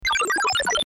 Typing.mp3